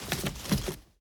Free Fantasy SFX Pack
Bow Attacks Hits and Blocks
Bow Put Away 1.ogg